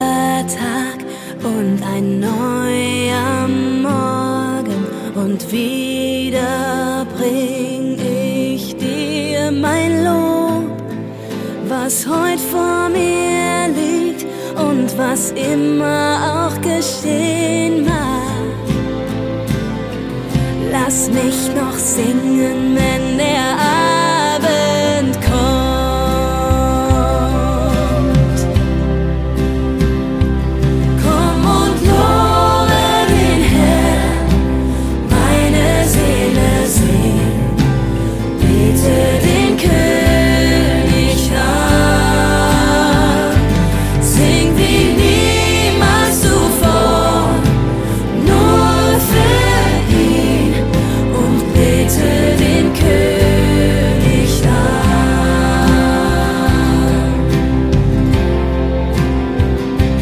Text) Worship 0,99 €